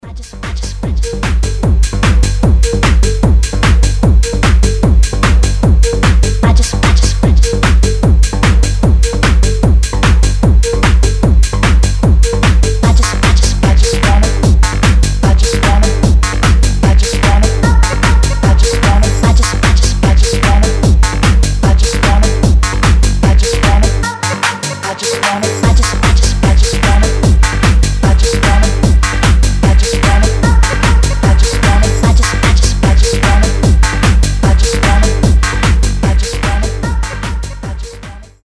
EDM
быстрые
динамичные
Техно